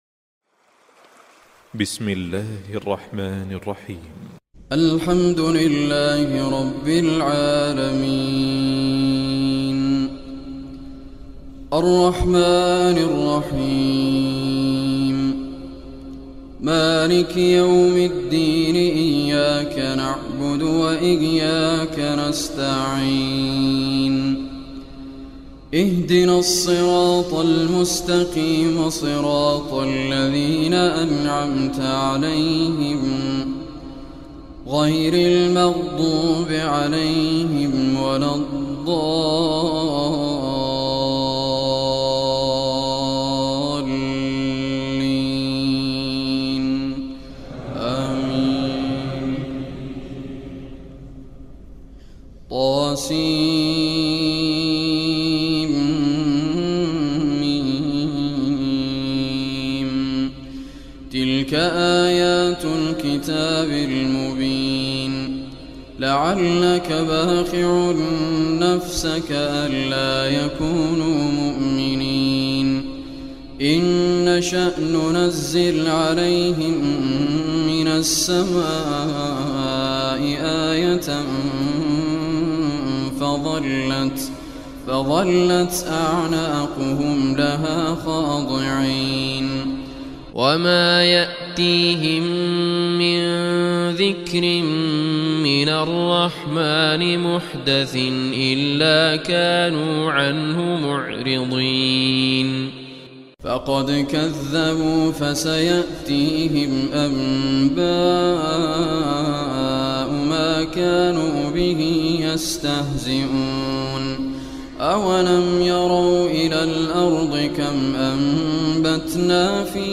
Surah Ash Shuraa Recitation by Sheikh Raad Kurdi
Surah Ash Shuraa, listen or play online mp3 tilawat / recitation in the beautiful voice of Sheikh Raad Al Kurdi.